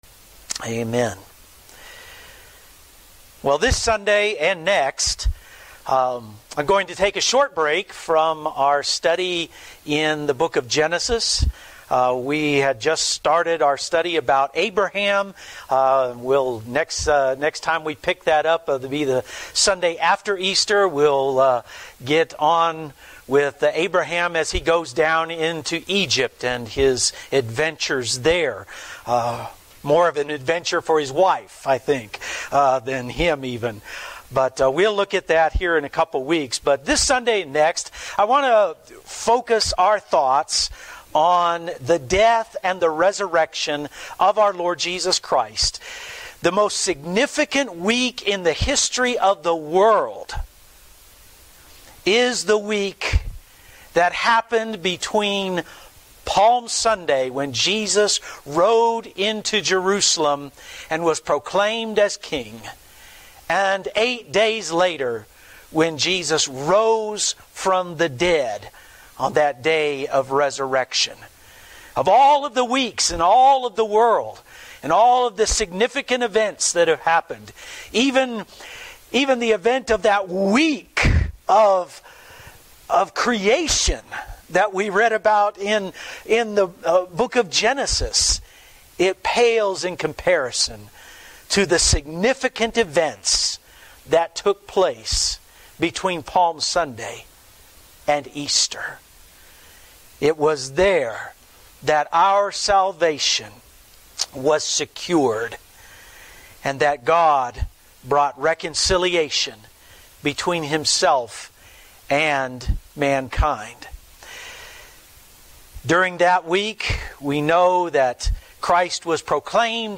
Today as we prepare for the Lord’s Supper, I want us to consider what the crucifixion of the Lord Jesus Christ means for us. In most of my sermons I preach expositional sermons on a paragraph or more of scripture.